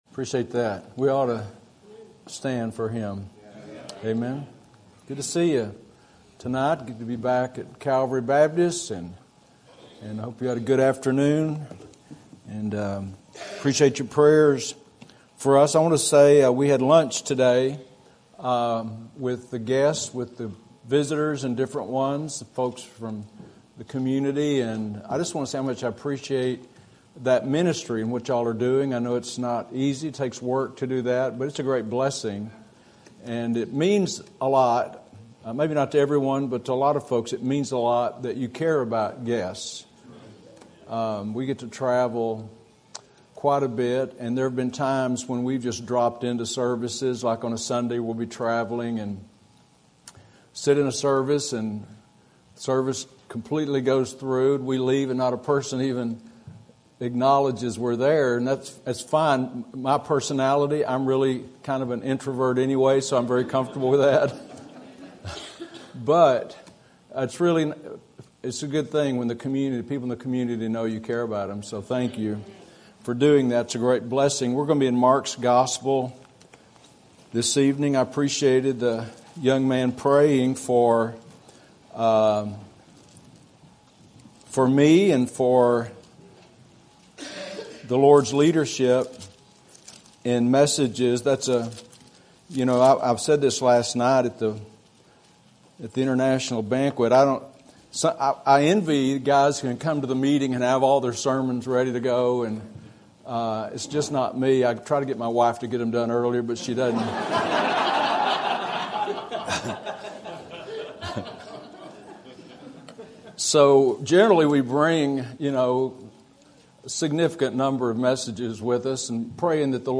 Sermon Topic: Missions Conference Sermon Type: Special Sermon Audio: Sermon download: Download (20.65 MB) Sermon Tags: Mark Missions Faith Corporately